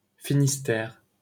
Finistère (/ˌfɪnɪˈstɛər/, French: [finistɛʁ]